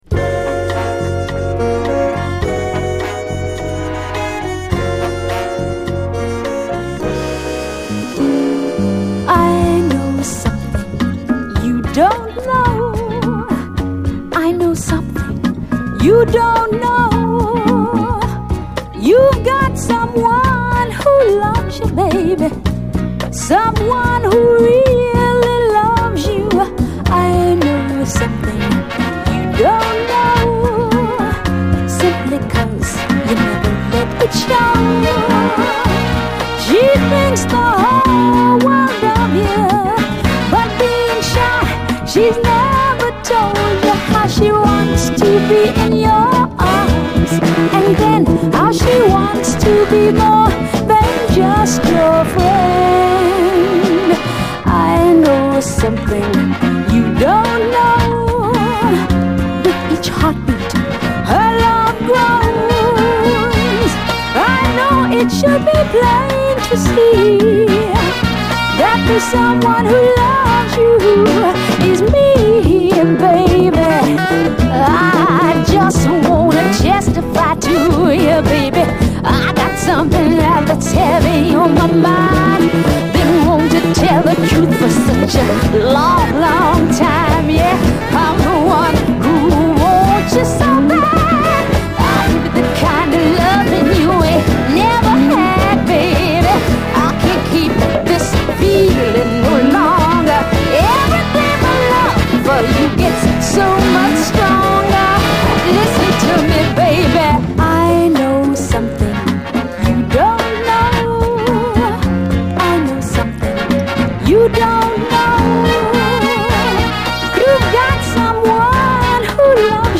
ファットなビートに加え女性コーラスのアレンジも素晴らしい
泣きの哀愁メロウ・ファンク
後半にドラム・ブレイクもあり。